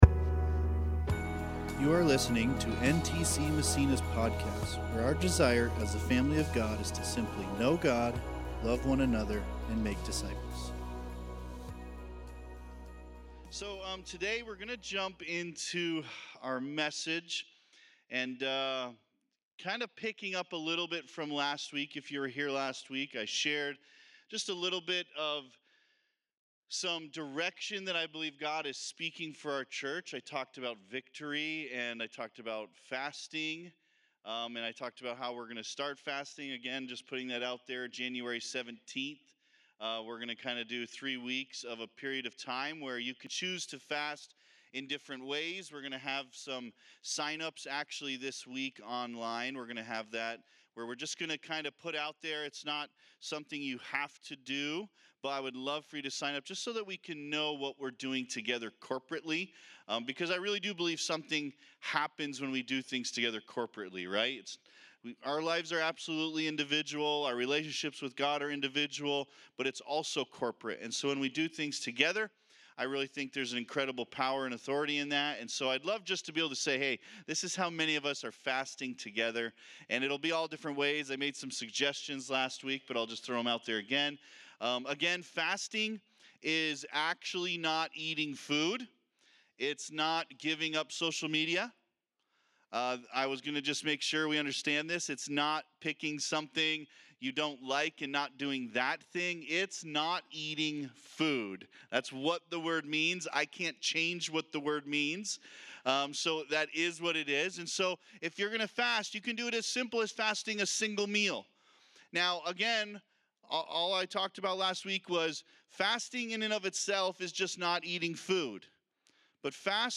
2025 Spiritual Warfare Preacher